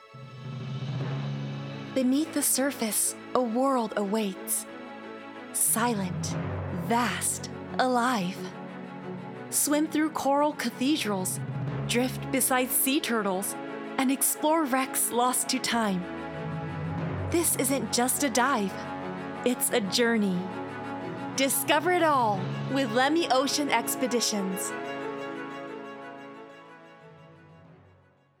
– ナレーション –
荘厳な